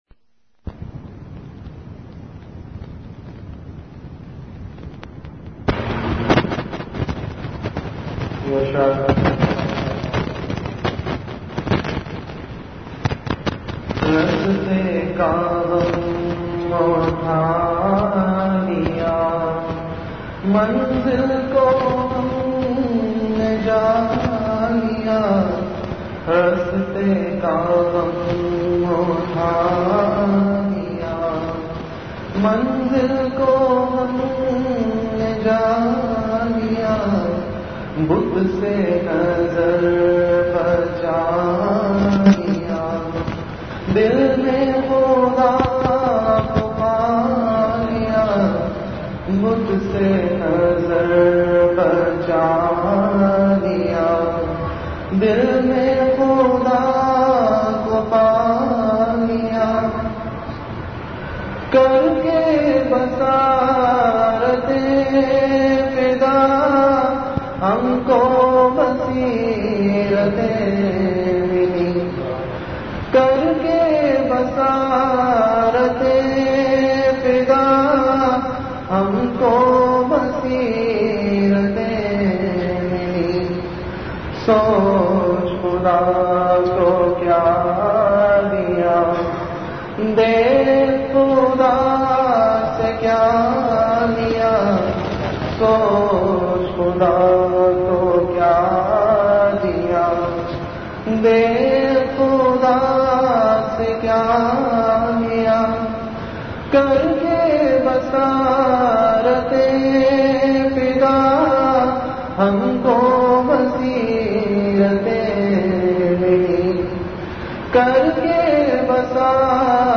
Majlis-e-Zikr
Event / Time After Isha Prayer